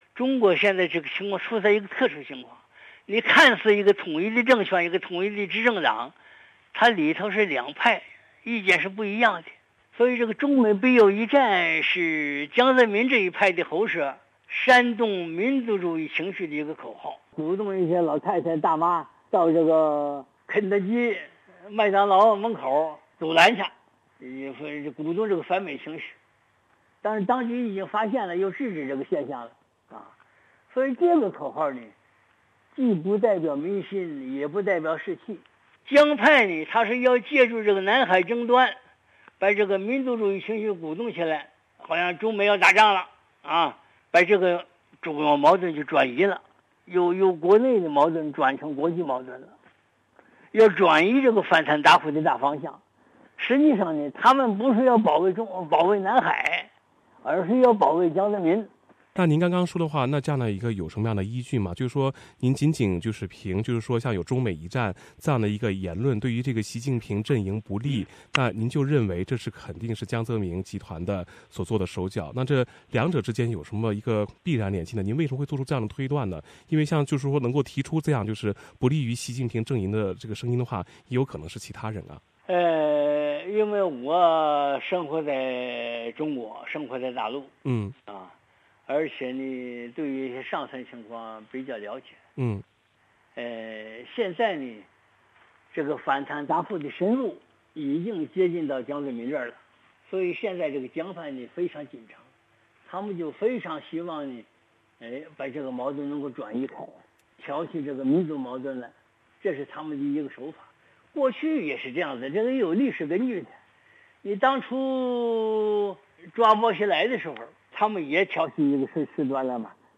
南海仲裁结果公布后，大陆舆论导向中出现一种鼓动战争的氛围，甚至有中美必有一战的说词。 原中国军事学院出版社社长辛子陵在接受本台记者采访时说，这是刘云山控制的中宣部煽动民族情绪的舆论，目的是为身陷打老虎反腐斗争中的江氏集团解围。